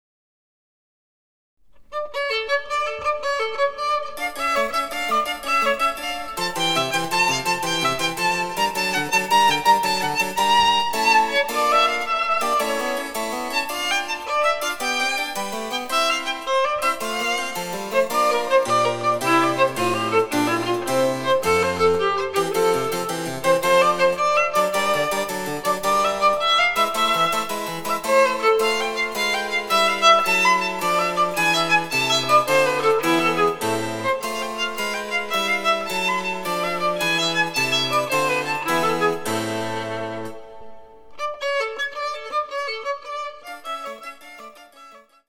■ヴァイオリンによる演奏（ニ短調）